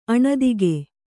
♪ aṇadige